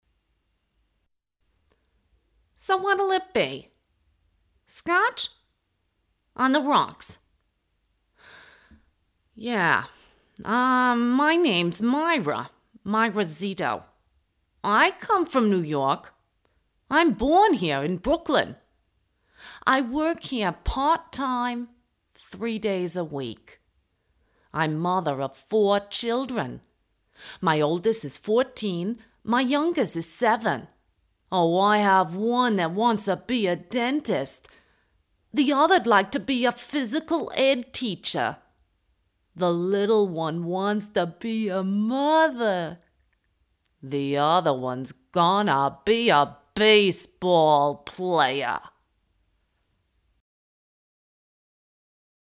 Sprecherin englisch uk
Sprechprobe: Werbung (Muttersprache):
english voice over artist uk